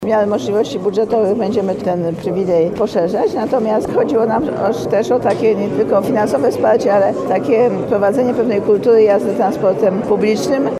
Jak zapewnia Hanna Gronkiewicz-Waltz, pomysł ten będzie rozpatrywany przez warszawski ratusz.